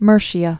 (mûrshē-ə, -shə)